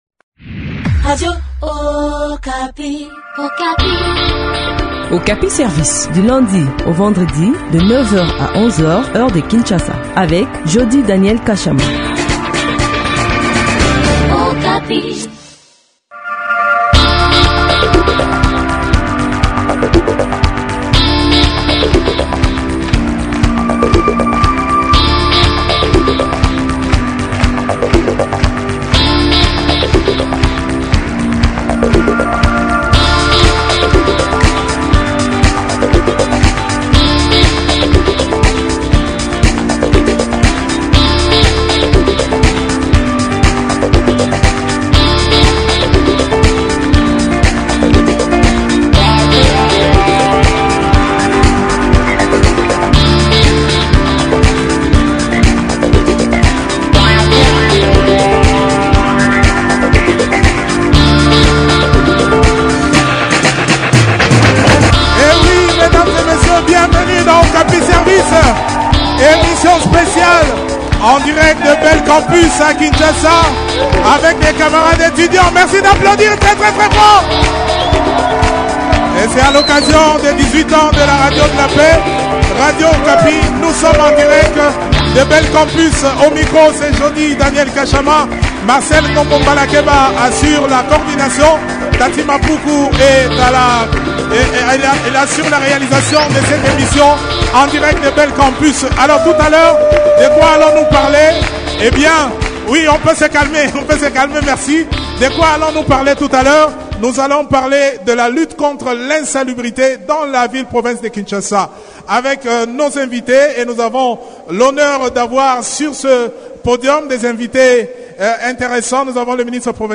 discute de ce sujet avec Tenge Litho Didier, ministre provincial de l’environnement.